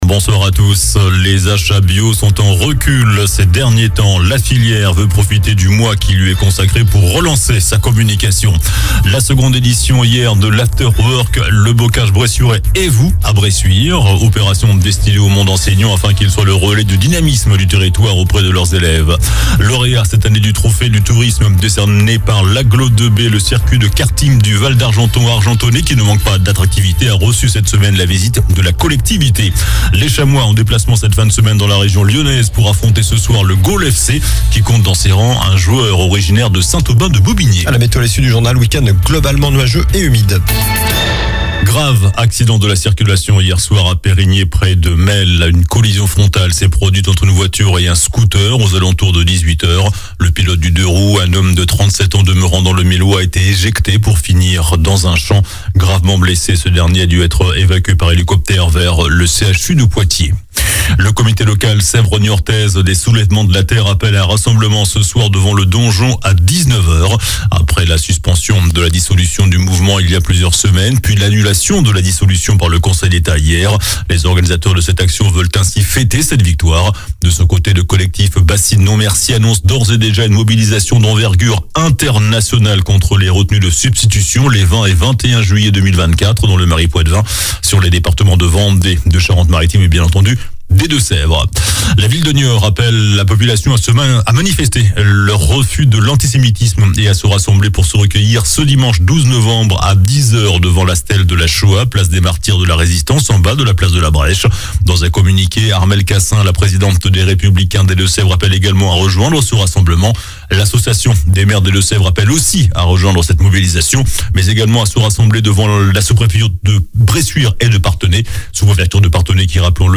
JOURNAL DU VENDREDI 10 NOVEMBRE ( SOIR )